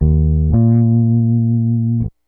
BASS 5.wav